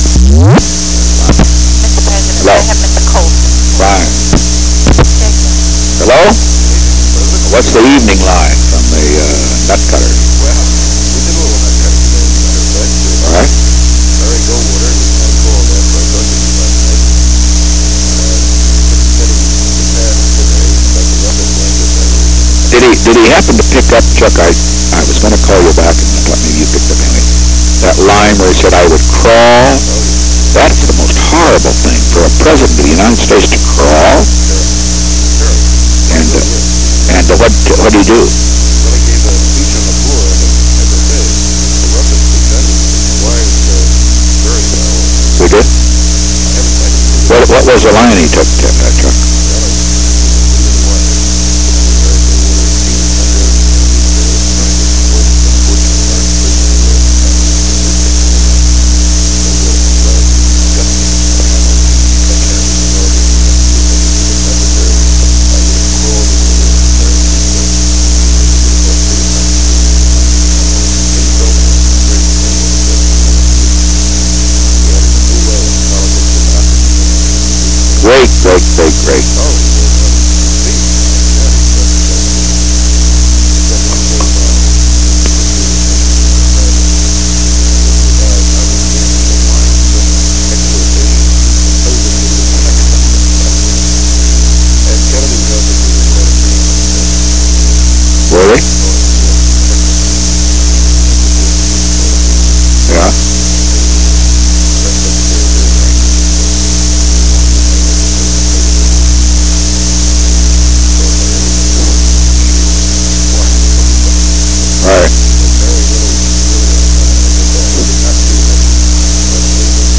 Conversation No. 10-34 Date: September 29, 1971 Time: 7:33 pm - 7:57 pm Location: White House Telephone The President talked with Charles W. Colson.